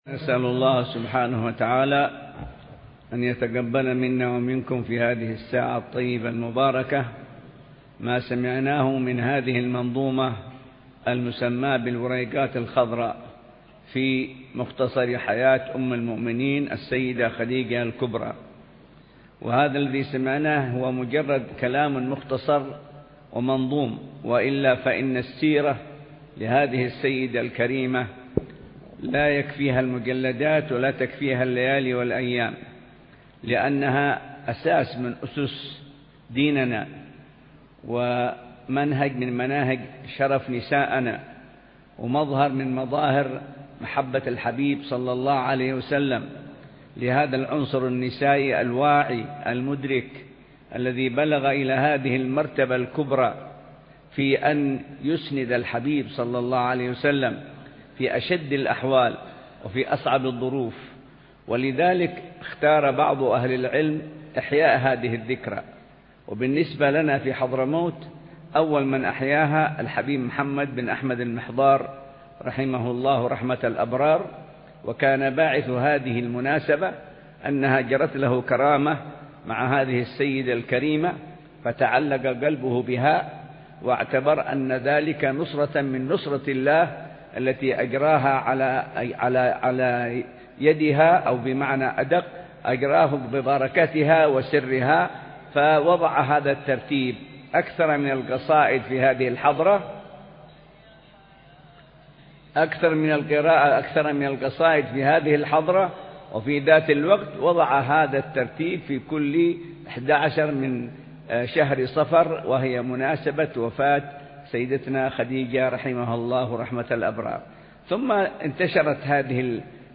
بمجلس قراءة منظومة الوريقات الخضراء في نظم مختصر حياة أم المؤمنين السيدة “خديجة الكبرى” مساء الجمعة 7 صفر 1439هـ